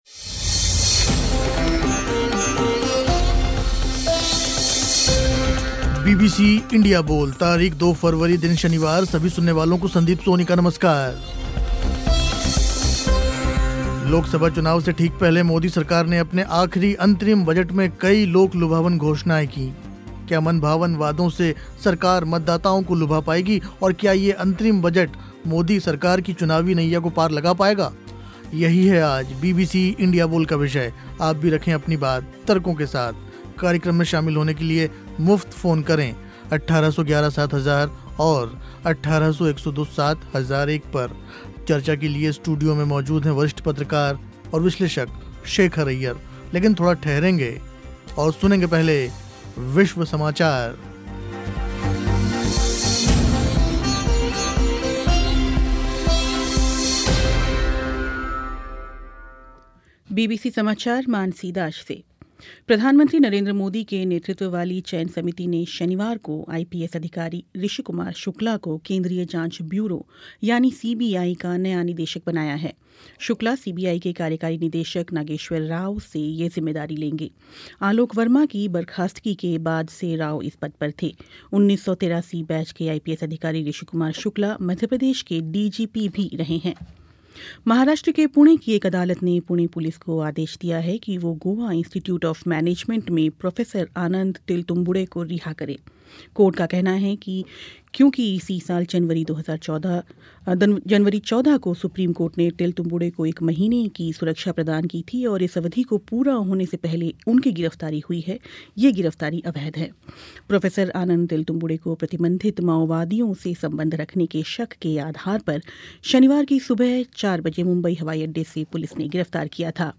दो फरवरी के इंडिया बोल में चर्चा हुई इसी विषय पर.